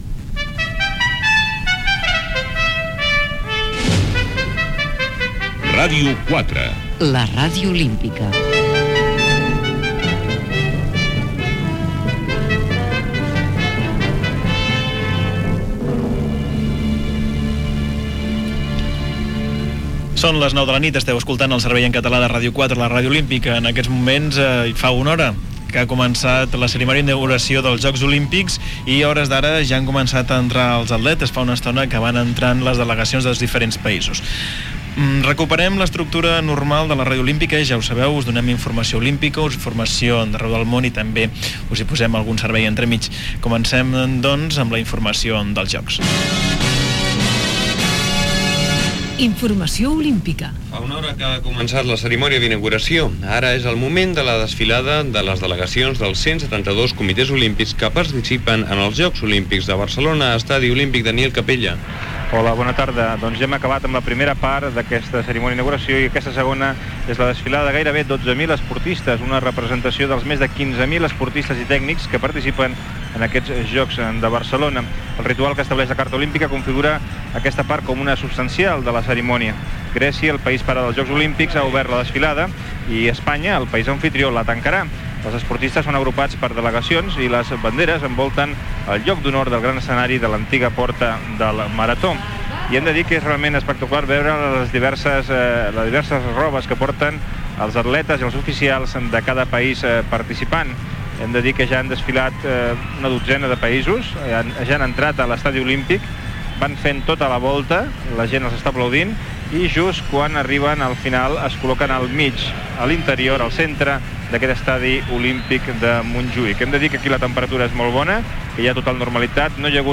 connexió amb l'Estadi Lluís Companys on s'està fent la cerimònia d'obertura dels Jocs Olímpics de Barcelona 1992, ambient a fora de l'estai. Notícies internacionals.
Gènere radiofònic Informatiu